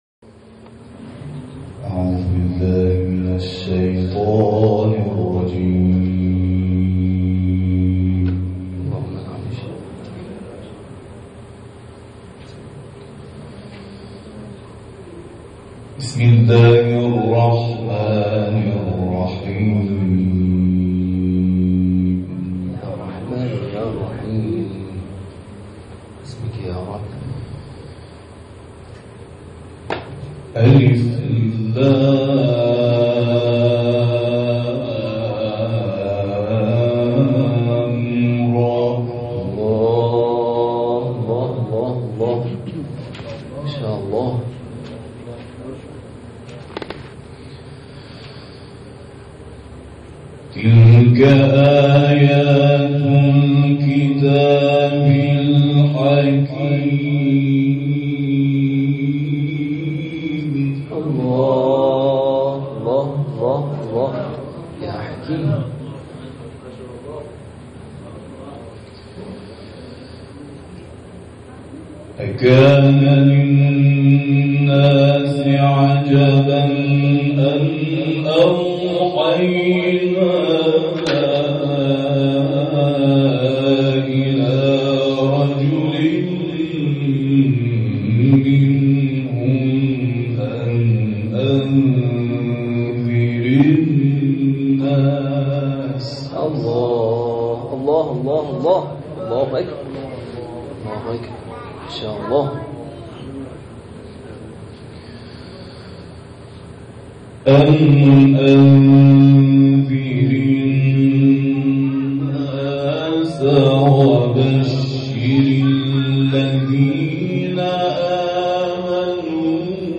تلاوت سوره «یونس»